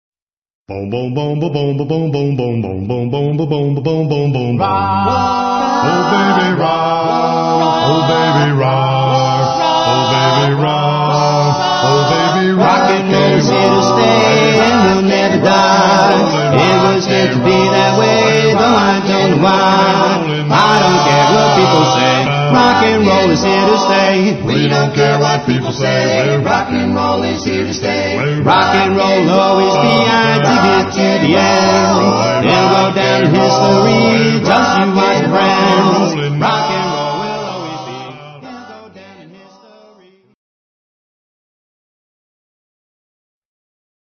authentic four-part harmonies